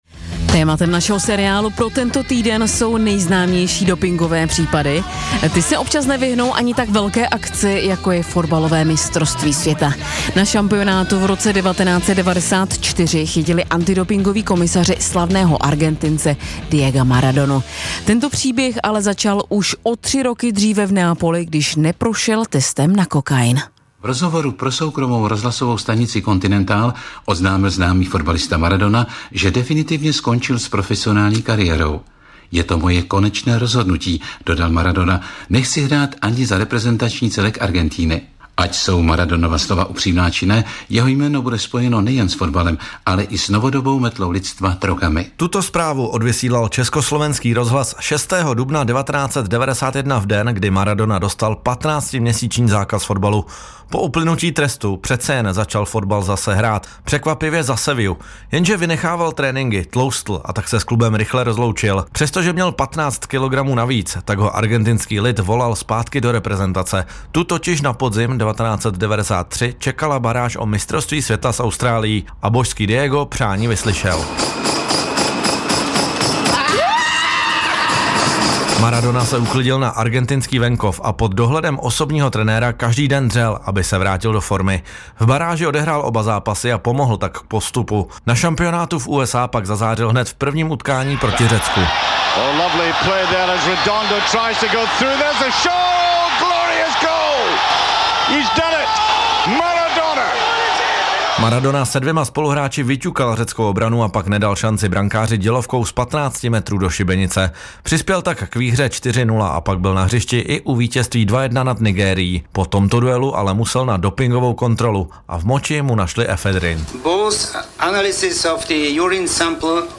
Páteční finiš Kateřiny Neumannové: Svého hosta zpovídá bývalá běžkyně na lyžích, olympijská vítězka ze ZOH v Turíně 2006, šestinásobná olympijská medailistka, dvojnásobná mistryně světa - 11.04.2025